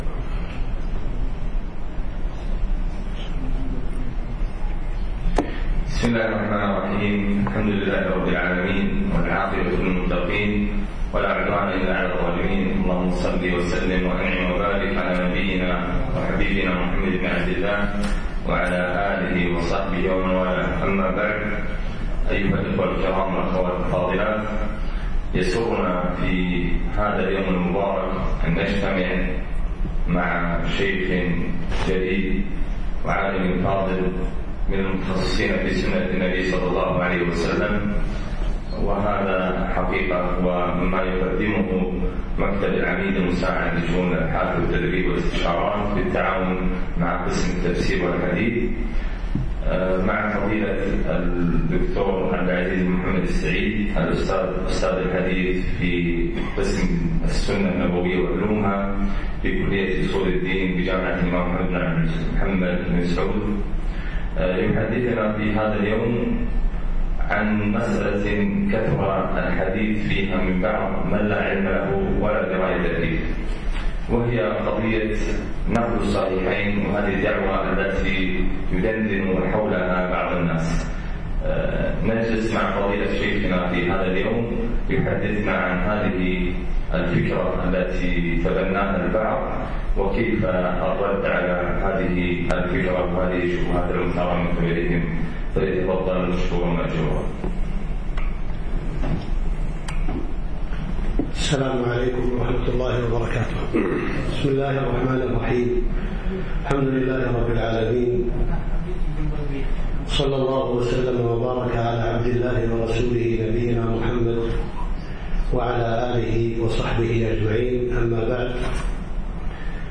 محاضرة نقد الصحيحين